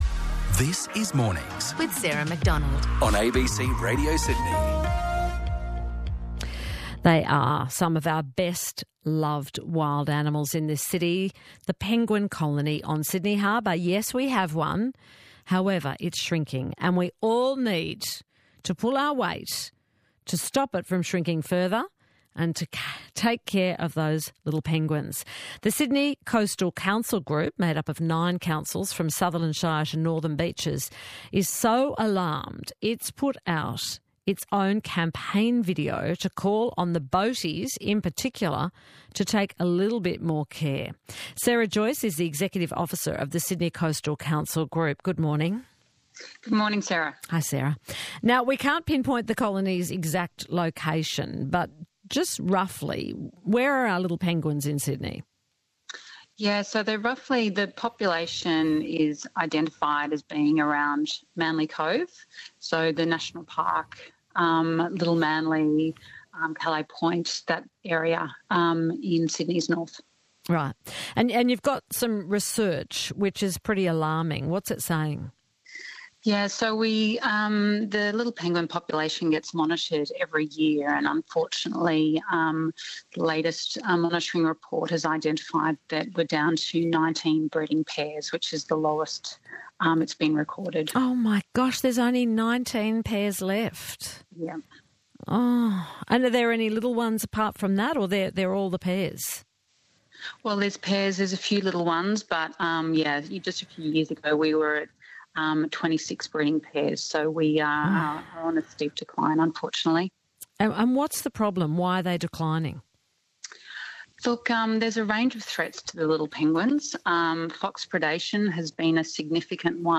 Little Penguin Recovery Program Featured on ABC Radio - Sydney Coastal Councils Group Inc